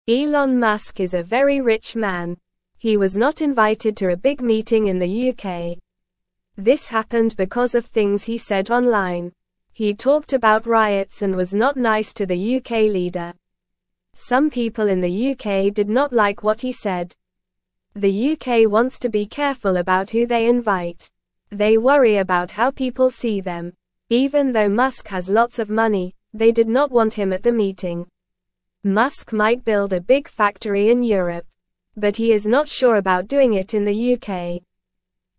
Normal
English-Newsroom-Beginner-NORMAL-Reading-Elon-Musk-not-invited-to-UK-meeting-upset.mp3